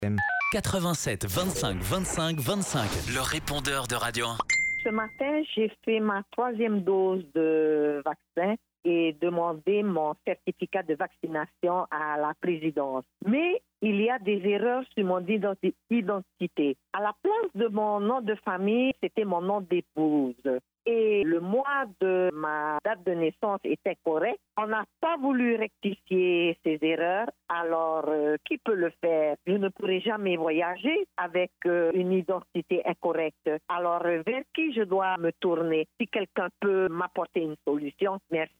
Répondeur de 6h30, le 13/01/2022